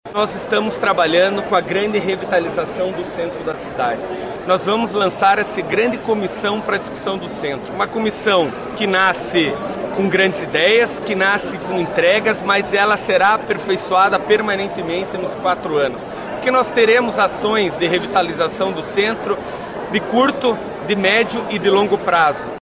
Desde que assumiu o Executivo Municipal, Eduardo Pimentel disse que pretende revitalizar a região central de Curitiba. Durante uma coletiva de imprensa realizada nesta sexta-feira (31), na Rua da Cidadania Matriz, na Praça Rui Barbosa, Pimentel disse que na próxima quinta-feira, dia 6 de fevereiro, vai lançar uma comissão para discutir sobre o assunto.